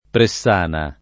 [ pre SS# na ]